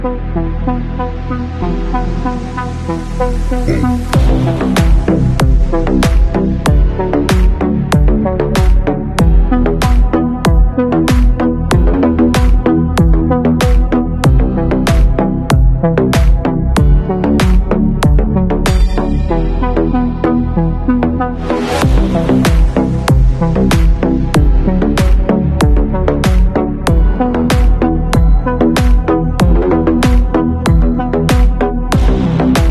Trance song combined with dj in house